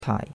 tai3.mp3